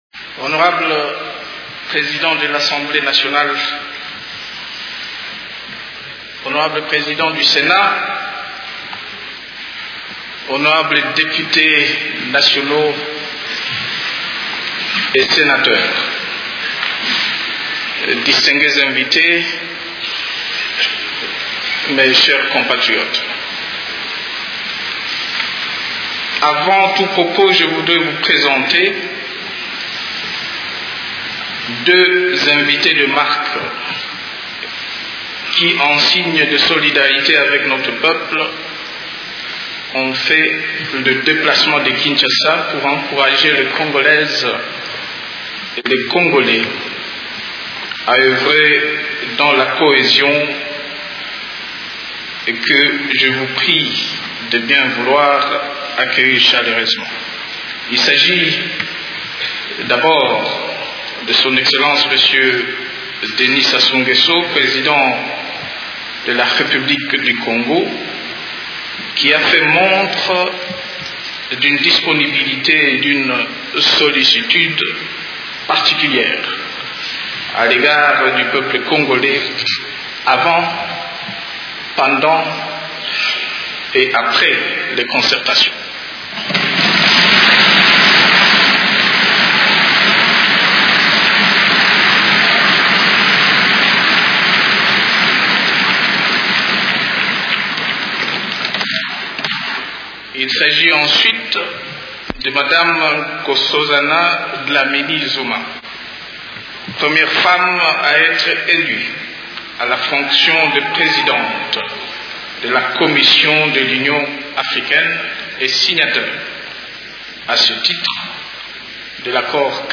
Devant les sénateurs et députés, Joseph Kabila a déclaré :
discour-de-Joseph-Kabila-Web.mp3